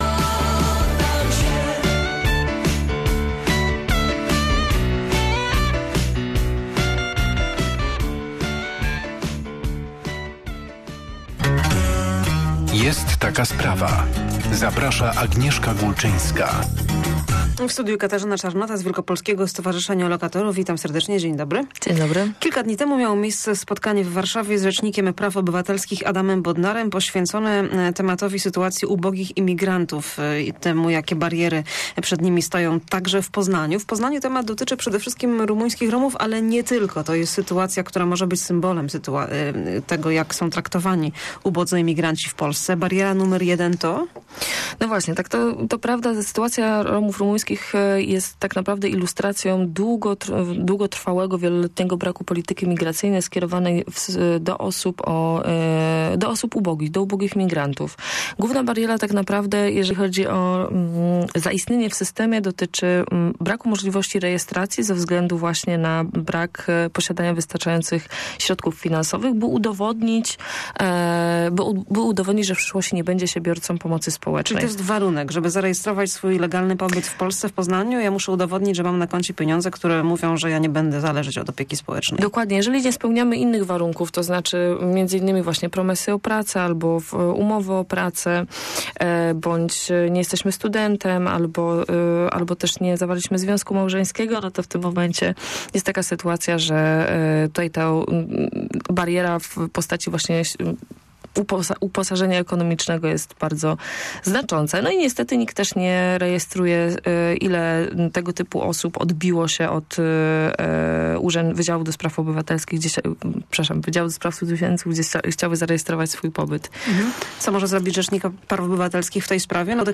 lrab778nnbizg47_rozmowa_o_romach.mp3